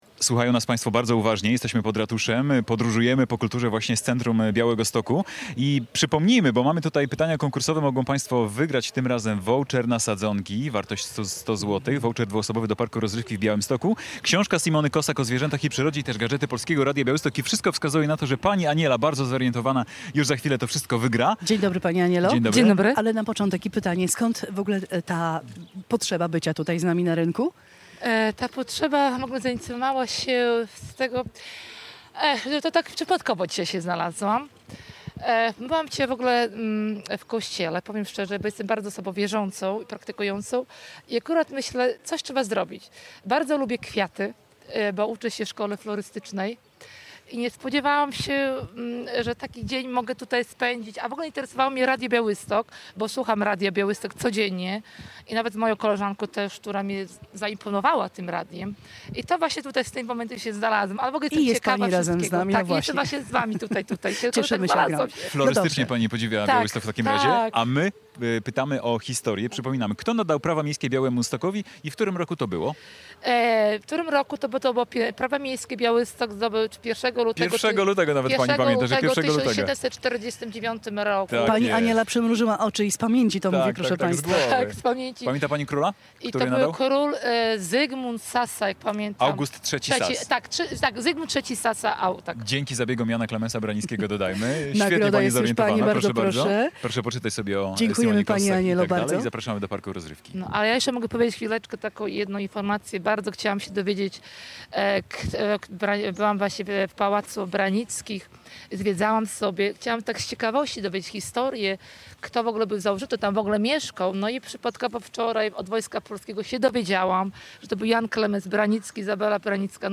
Polskie Radio Białystok w niedzielę otworzyło mobilne studio przy Ratuszu.